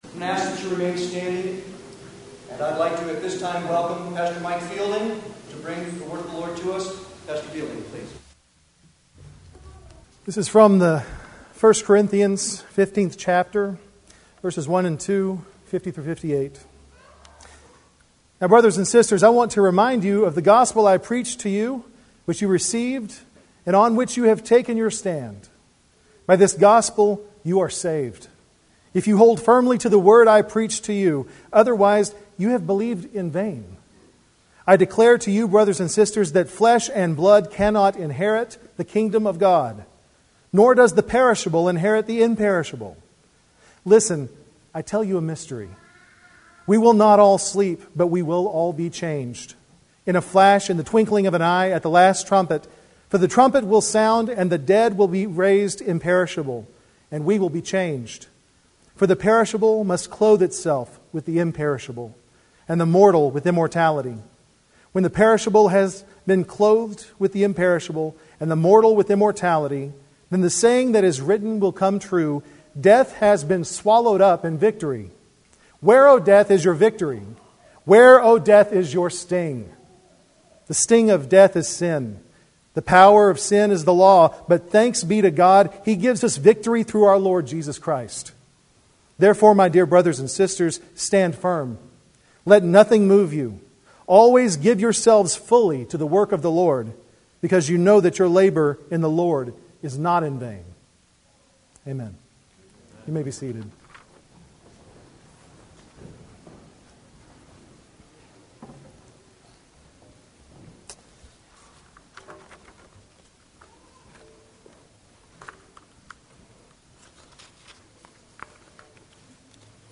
Series: Sermons by visiting preachers
Service Type: Sunday worship